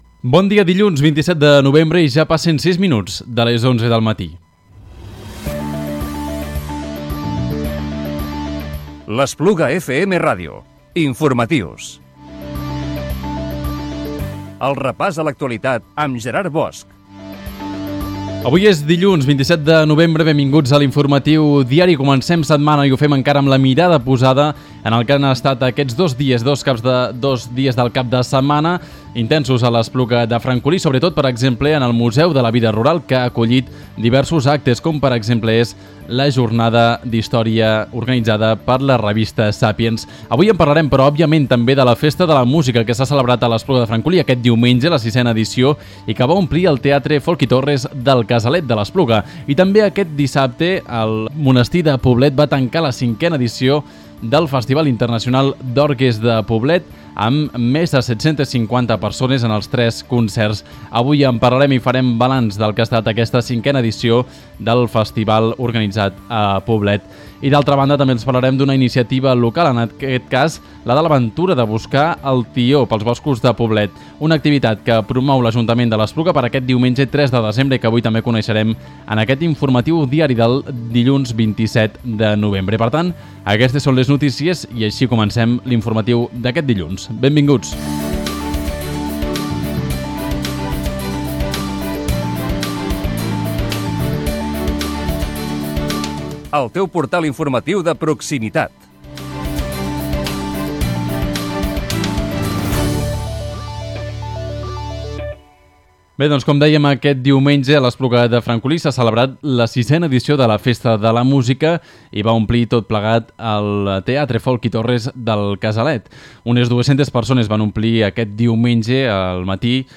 Informatiu Diari del dilluns 27 de novembre del 2017
Finalment, hem entrevistat a la regidora de festes de l’Espluga, Erika Soriano, per parlar de l’activitat d’anar a buscar el tió que s’estrenarà aquest diumenge.